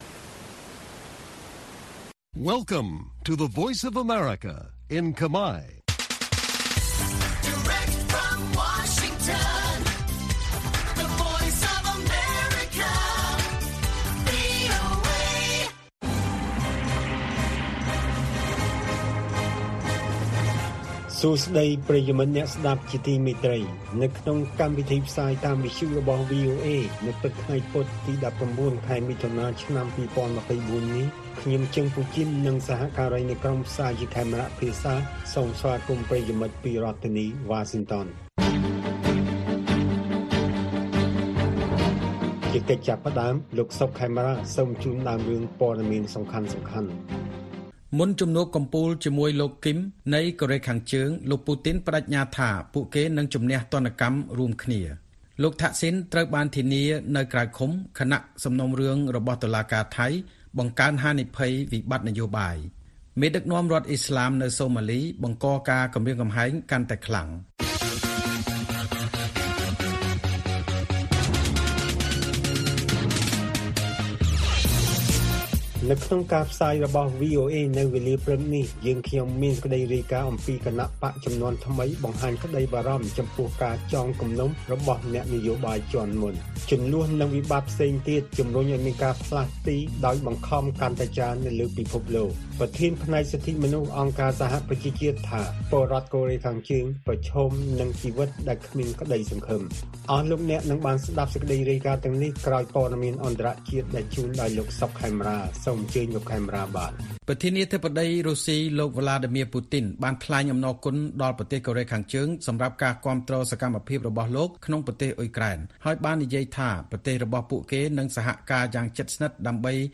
Listen Live - កម្មវិធីវិទ្យុពេលព្រឹក - វីអូអេ - VOA Khmer